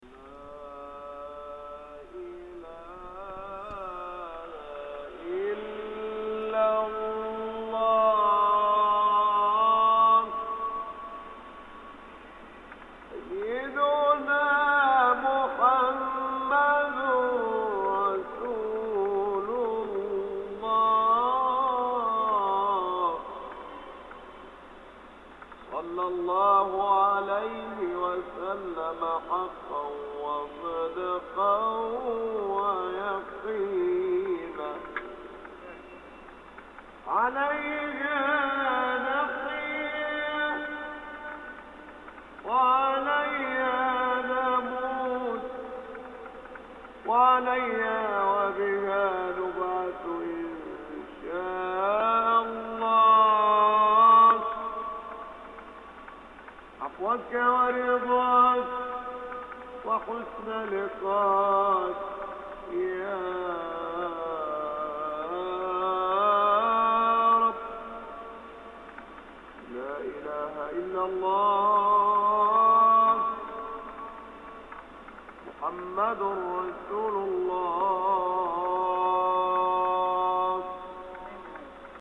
Muezzin’s Song (click to play)